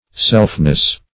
Selfness \Self"ness\, n.